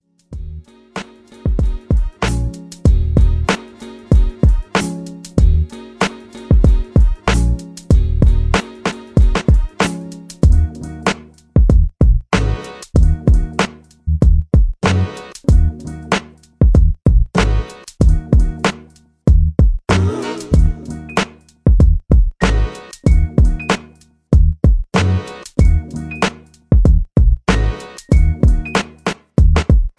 Soulful Hip Hop Sampled Beat